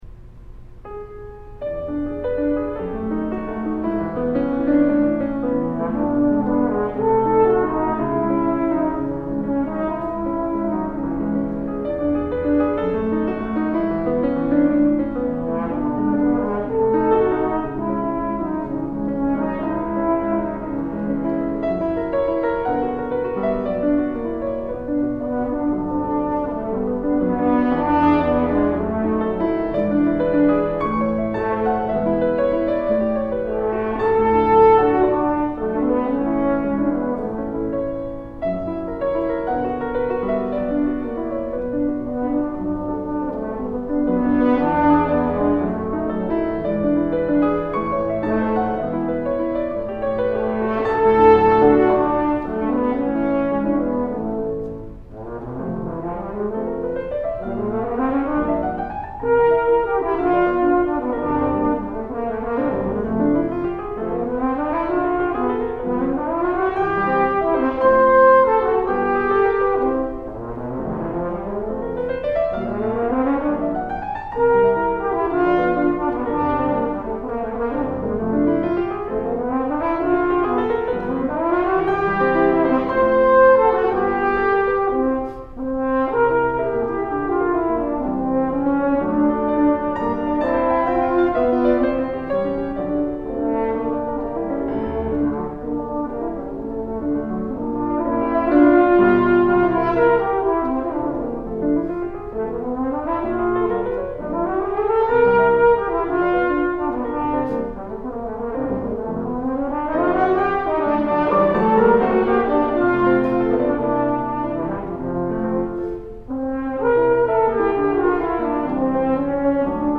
Thoughts on Teaching and Playing the Horn
piano
See the links below to listen to my live performance of all three movements.